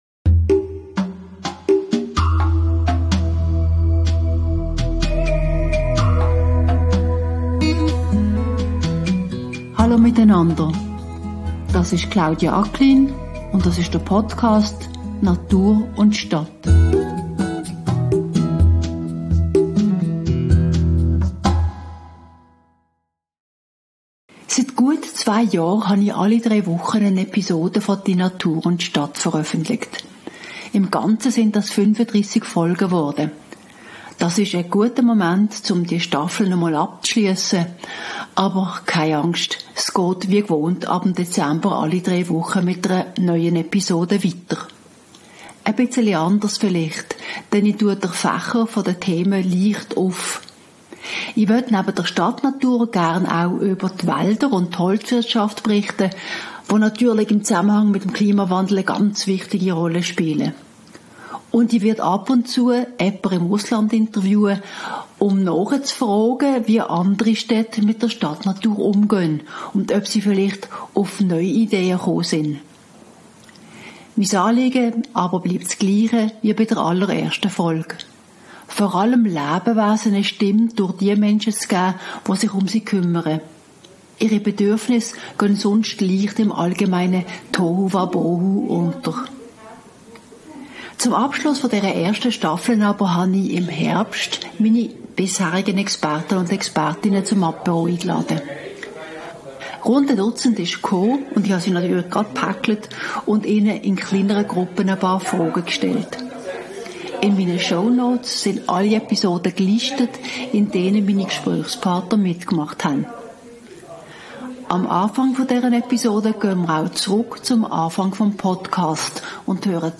Die Folge zum Staffelende bildet eine kleine Zäsur mit einem bunten Strauss von Meinungen: Das Staffelende wollte ich mit meinen bisherigen Gesprächspartner*innen feiern und habe sie deshalb zu einem Apéro eingeladen. Rund ein Dutzend ist gekommen und ich habe gleich in Gruppen von eins bis drei Personen diskutiert. Entstanden sind kurze Einschätzungen aus ihrer jeweiligen Fachperspektive zu Fragen wie: Was steht mehr Biodiversität, Tierrechten oder Massnahmen im städtischen Umfeld noch immer im Weg?